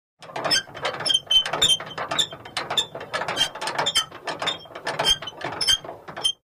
Скрип при трении металла вариант 3